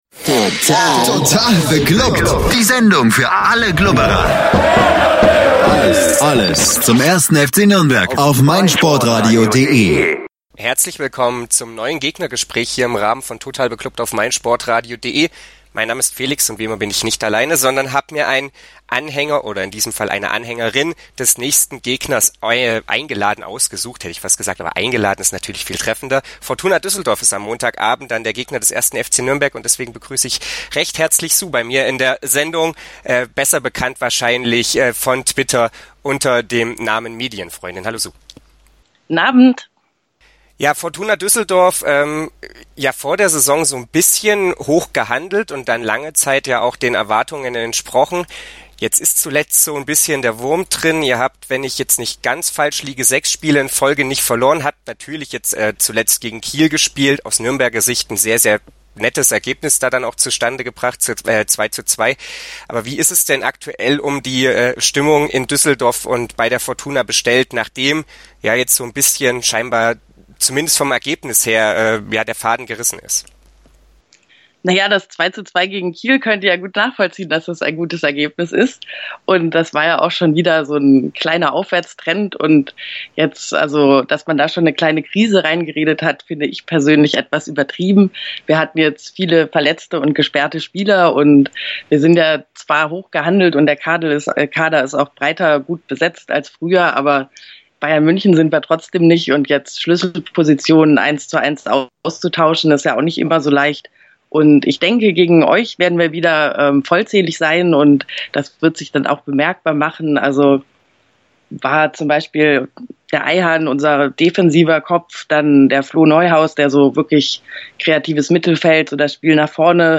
Gegnergespräch: Düsseldorf-FCN ~ Total beglubbt Podcast